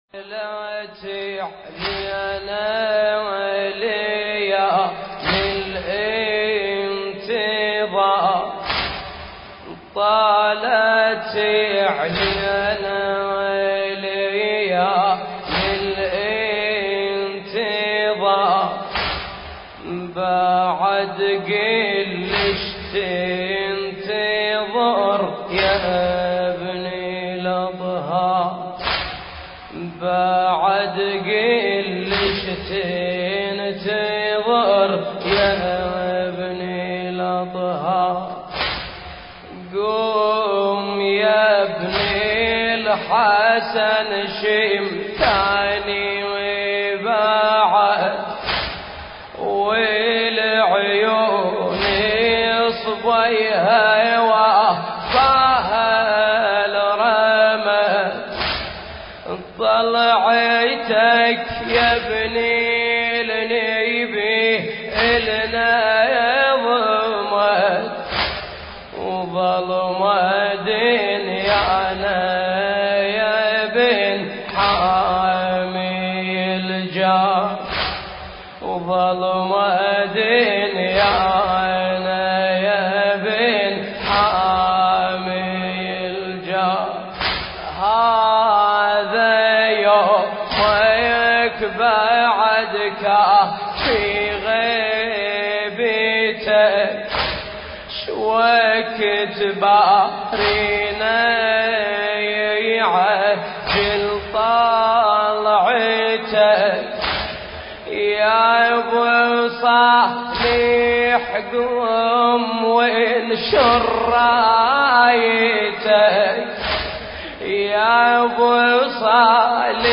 حسينية آلبو حمد – دولة الكويت